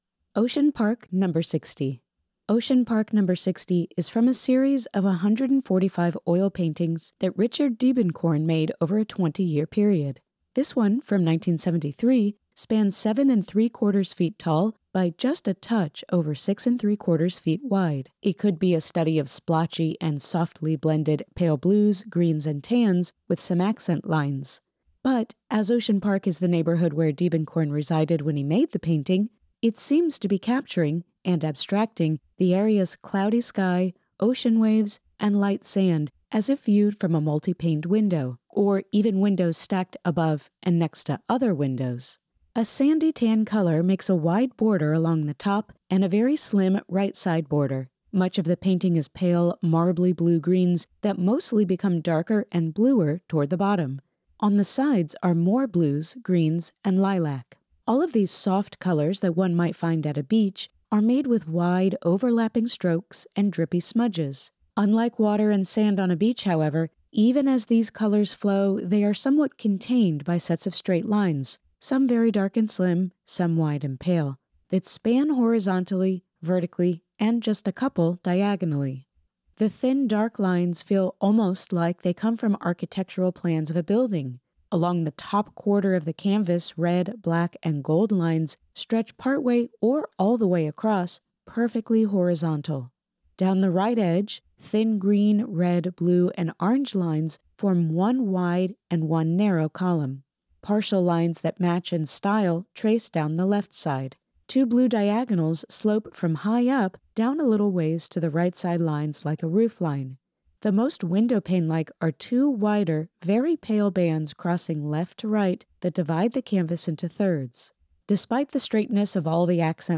Audio Description (02:31)